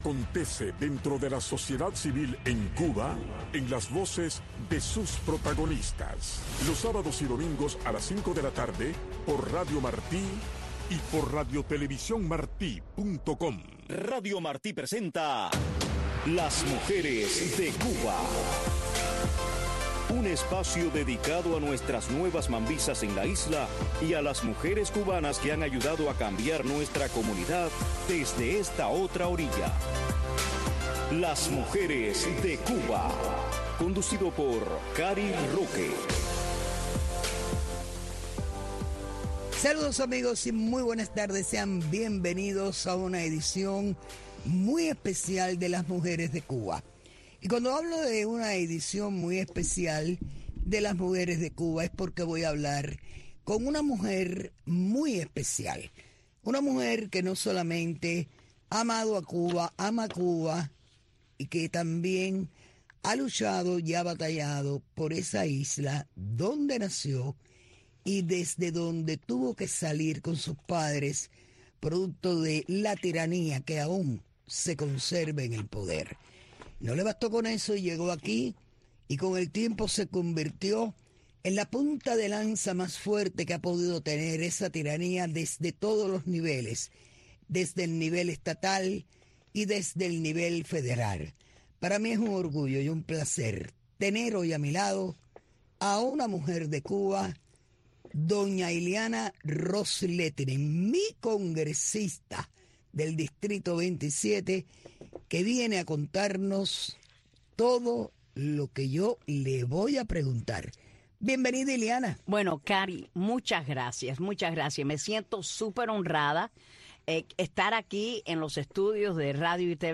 Un programa que busca resaltar a las mujeres cubanas que marcan pauta en nuestra comunidad y en la isla. Y es un acercamiento a sus raíces, sus historias de éxitos y sus comienzos en la lucha contra la dictadura. Un programa narrado en primera persona por las protagonistas de nuestra historia.